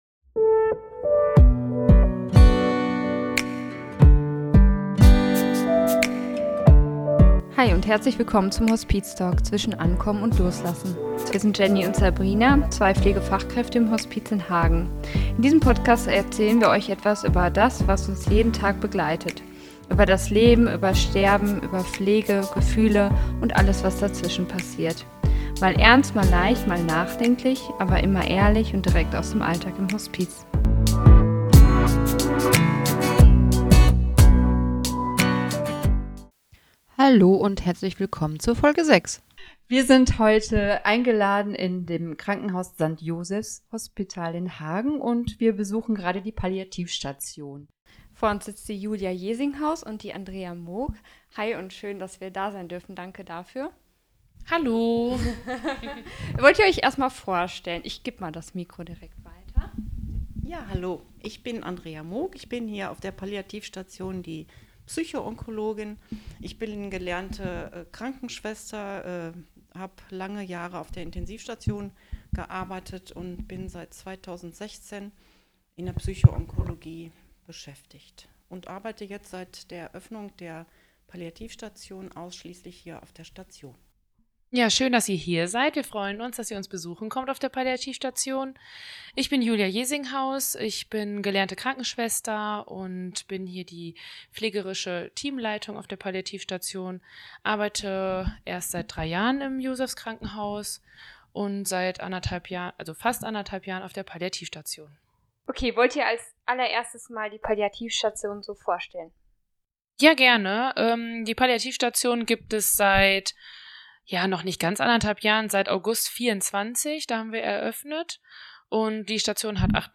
Beschreibung vor 1 Woche In dieser Folge von Hospiztalk waren wir zu Besuch auf der Palliativstation im St. Josefs-Hospital in Hagen.